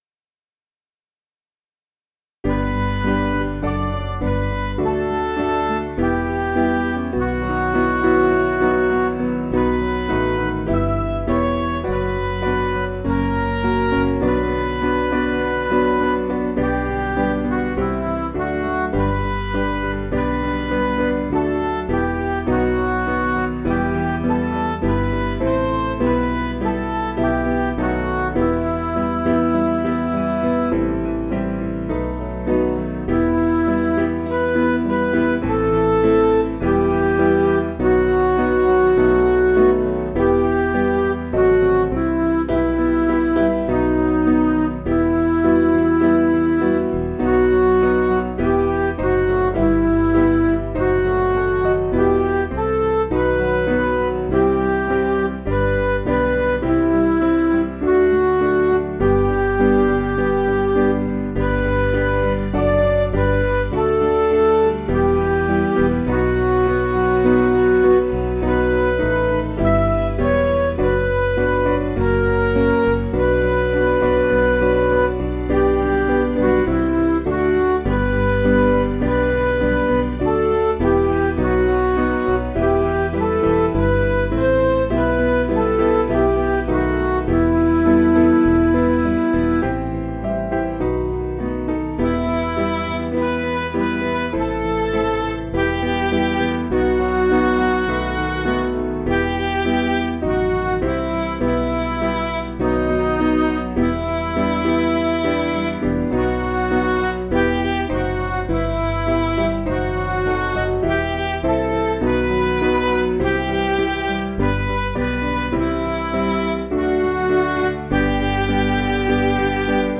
Piano & Instrumental
(CM)   4/Em